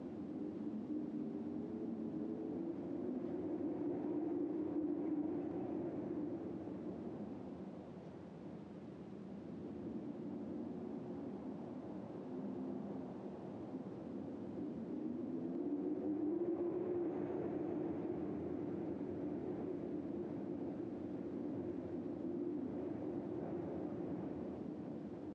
base-wind-fulgora.ogg